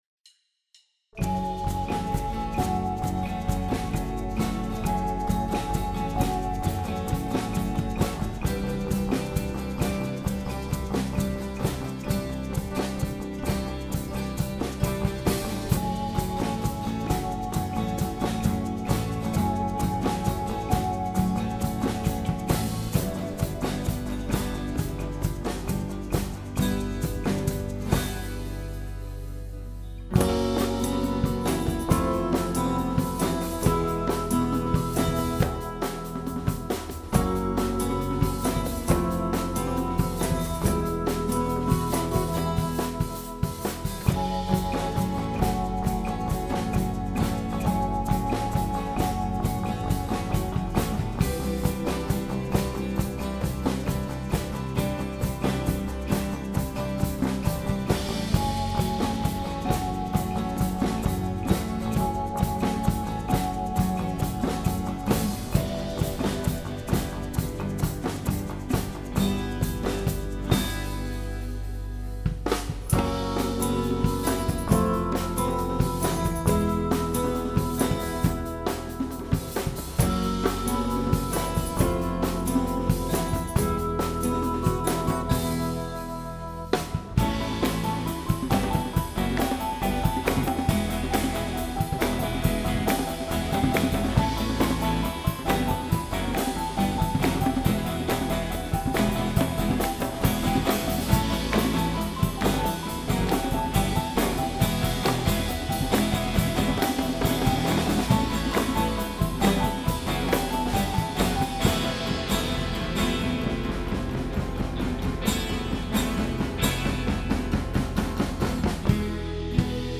This is my latest mix with my current band. It isn't mastered but I like where everything is sitting so far. All that is missing is the vocals.